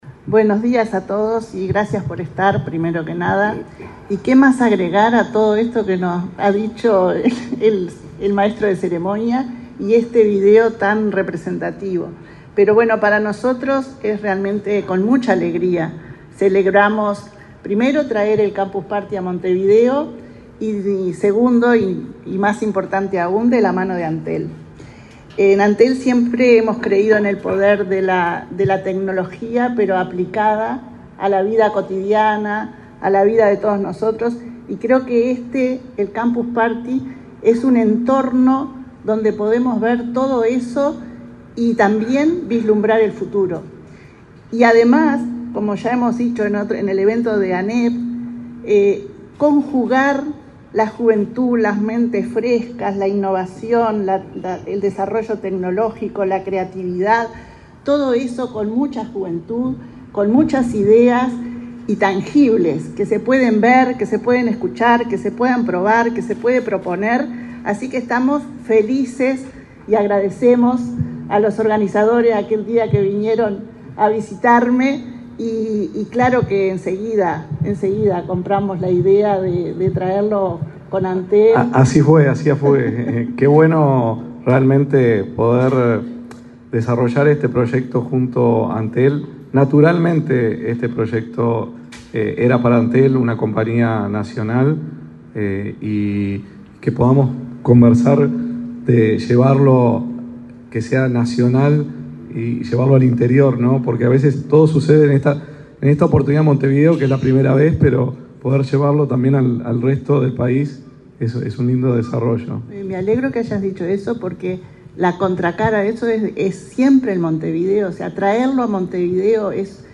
Palabras de la presidenta de Antel, Annabela Suburu
Palabras de la presidenta de Antel, Annabela Suburu 05/08/2024 Compartir Facebook X Copiar enlace WhatsApp LinkedIn La presidenta de Antel, Annabela Suburu, participó, este lunes 5, en el lanzamiento de Campus Party, un evento de innovación y tecnología que por primera vez tendrá lugar en Montevideo.